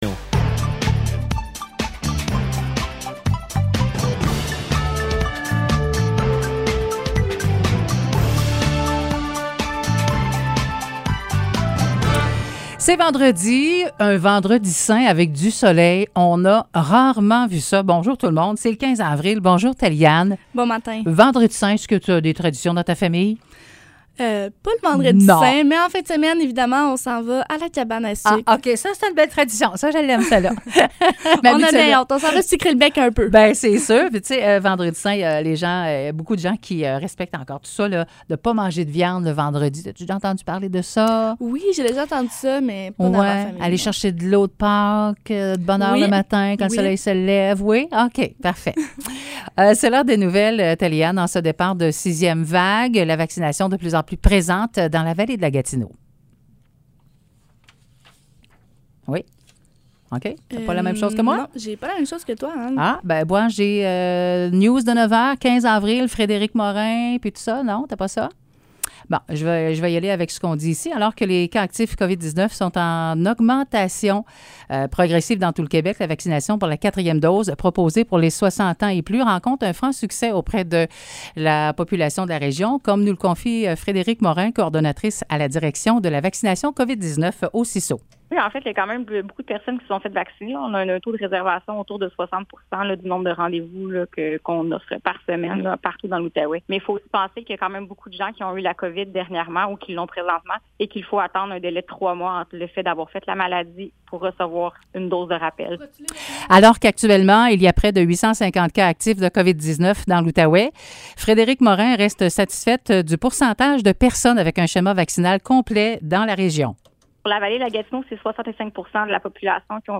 Nouvelles locales - 15 avril 2022 - 9 h